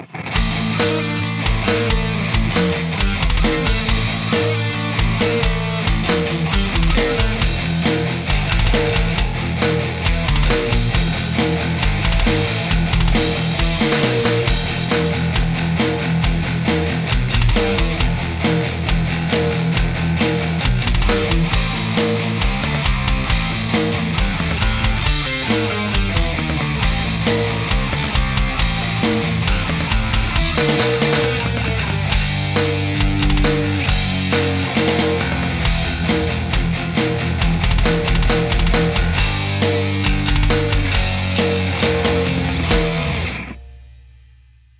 Tutorial Chitarra, Tutorial Metal